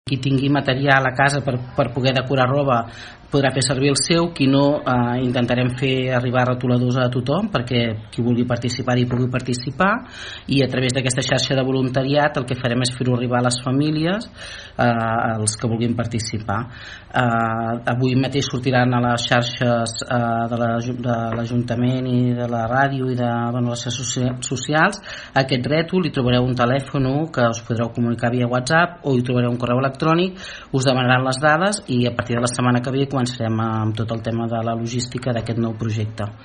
La tinent d’alcalde explica que a través dels canals de comunicació de l’Ajuntament es faran públiques les vies de contacte per inscriure’s a aquesta iniciativa.